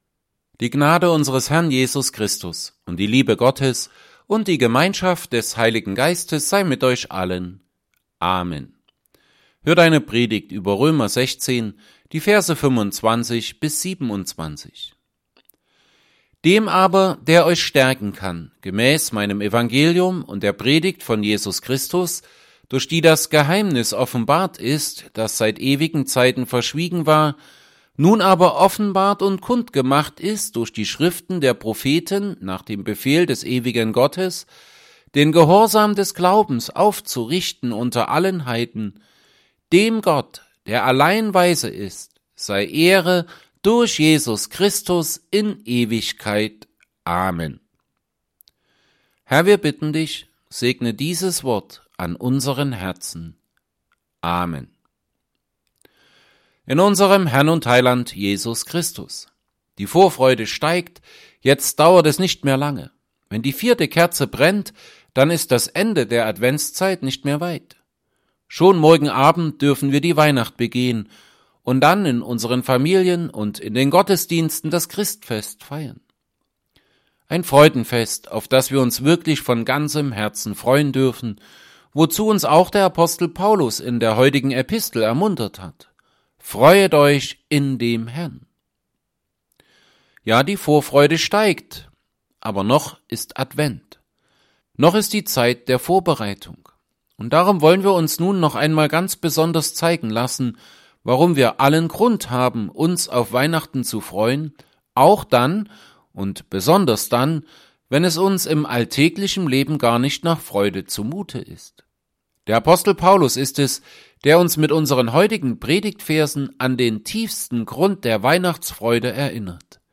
Epistelpredigten Passage: Romans 16:25-27 Gottesdienst: Gottesdienst %todo_render% Dateien zum Herunterladen Notizen « 3.